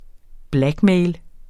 Udtale [ ˈblagˌmεjl ]